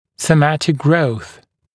[sə’mætɪk grəuθ][сэ’мэтик гроус]соматический рост